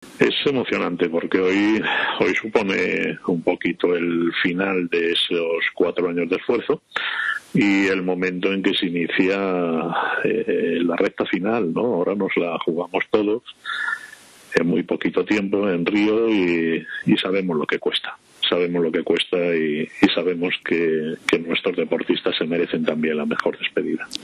Autoridades y miembros del equipo paralímpico posan en la despedida ante la sede del Conejo Superior de DeportesSAR la infanta doña Elena y la vicepresidenta del Gobierno en funciones, Soraya Sáenz de Santamaría, presidieron en Madrid el multitudinario acto de despedida del Equipo Paralímpico Español que viaja a Río de Janeiro para participar en los Juegos Paralímpicos, que se celebran entre el 7 y el 18 de septiembre.